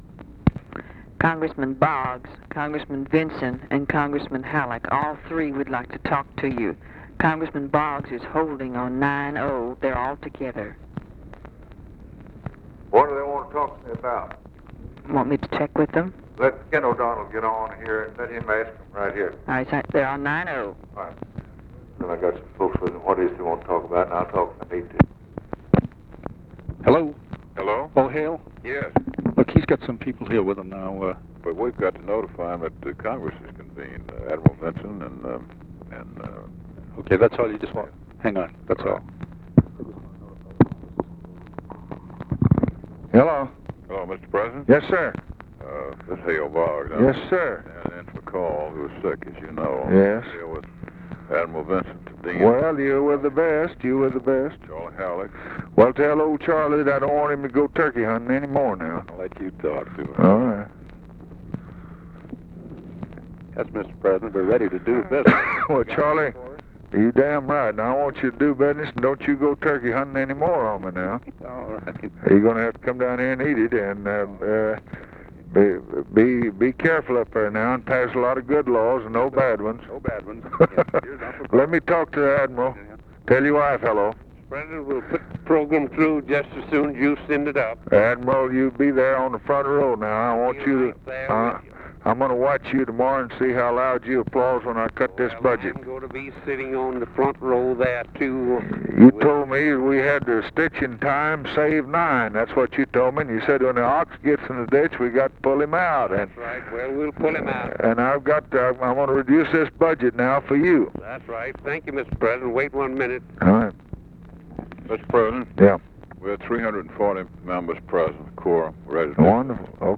Conversation with HALE BOGGS, CHARLES HALLECK, CARL VINSON and KEN O'DONNELL, January 7, 1964